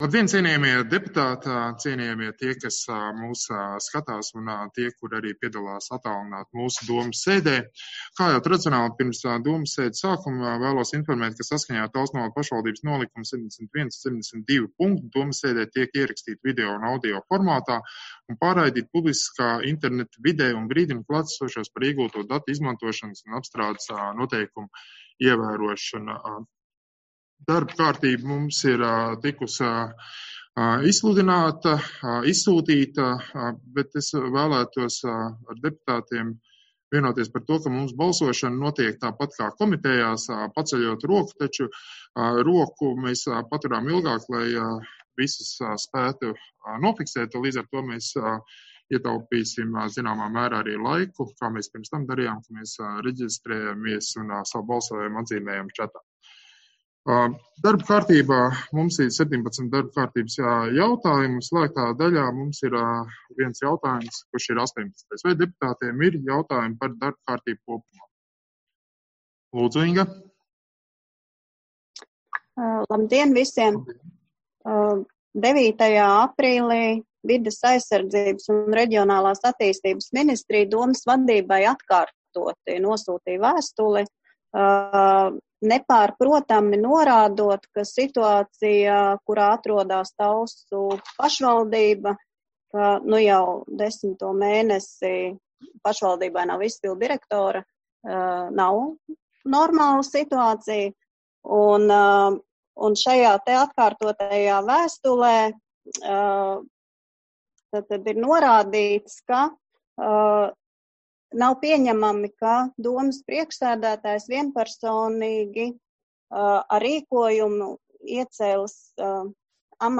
Balss ātrums Publicēts: 16.04.2020. Protokola tēma Domes sēde Protokola gads 2020 Lejupielādēt: 10.